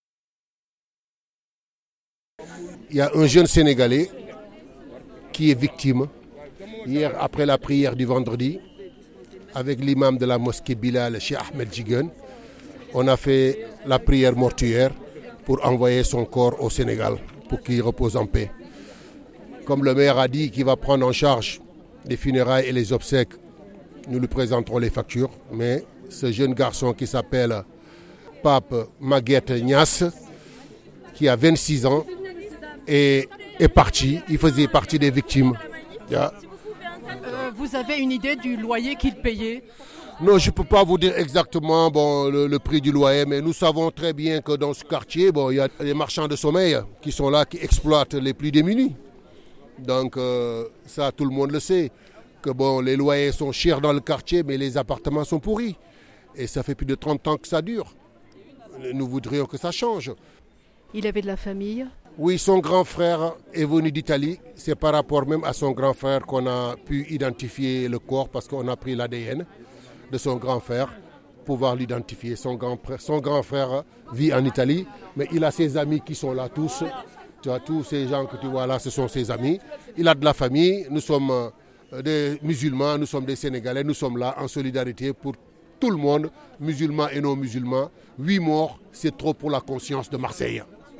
L’un d’entre-eux parle de celui «qui est parti». Dénonce «des marchands de sommeil qui exploitent les plus démunis.